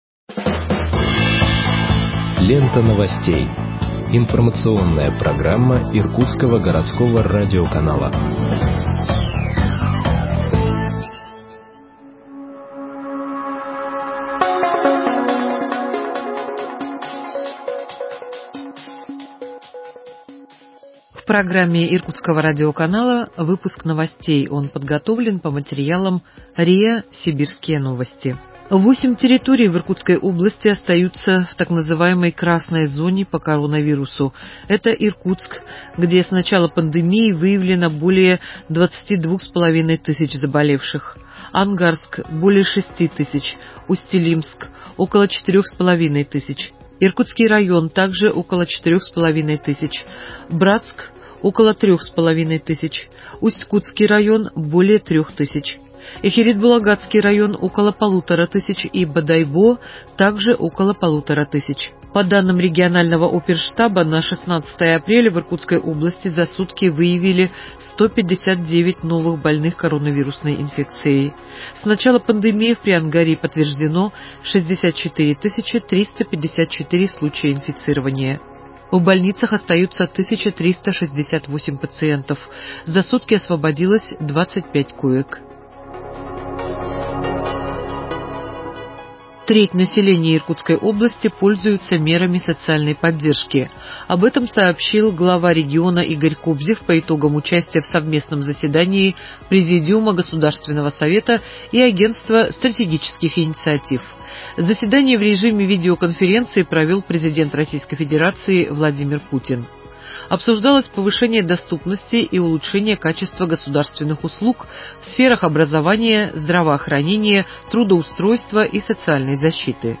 Выпуск новостей в подкастах газеты Иркутск от 16.04.2021 № 2